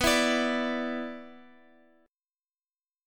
Bmbb5 chord
B-Minor Double Flat 5th-B-x,x,x,6,7,9-1-down-Guitar-Open F.m4a